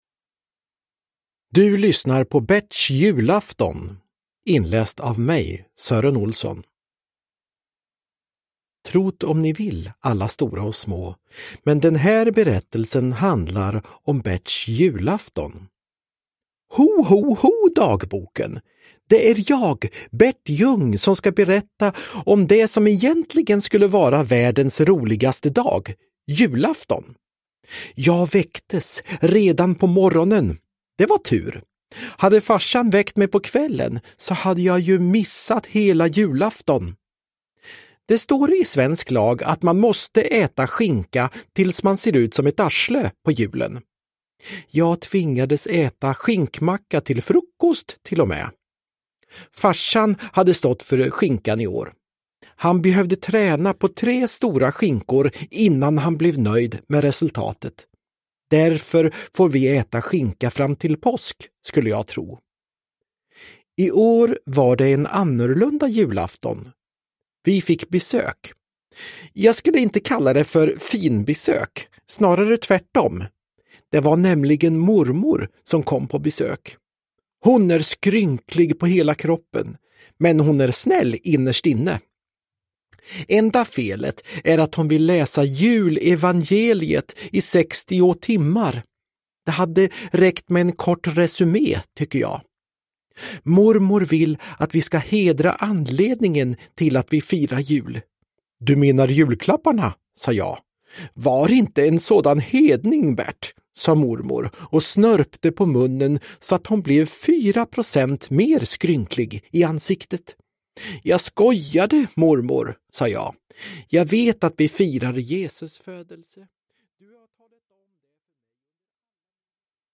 Uppläsare: Sören Olsson, Anders Jacobsson